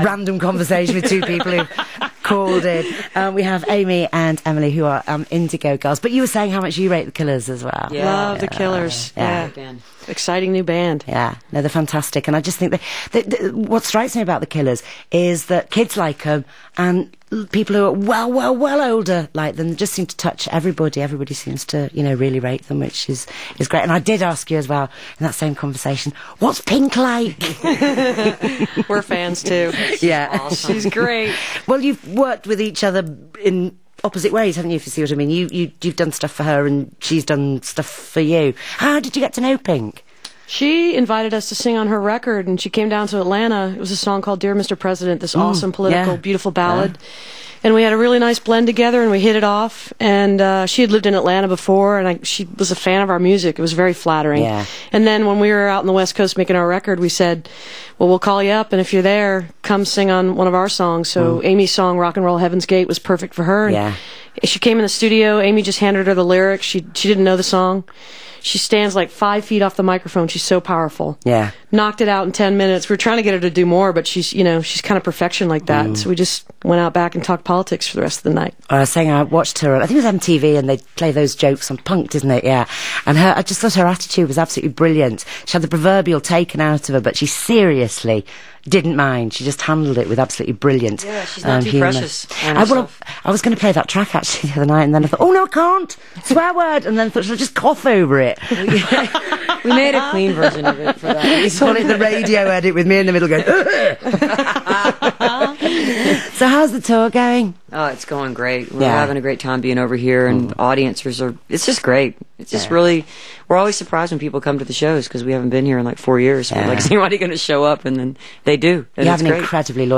01. interview (3:12)